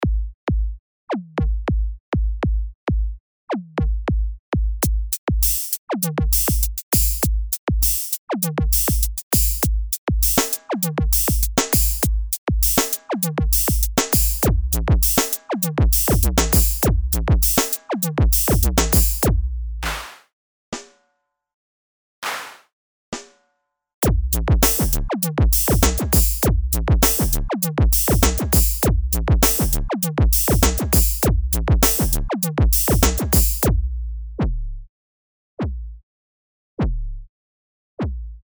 Fertige Dance-Presets
Viele der Dance-Presets sind sehr straight; teilweise trifft man auch auf Vorlagen, die kein vollständiges Paket aus Grooves liefern, sondern lediglich Entwürfe für eine eigene Ausarbeitung darstellen.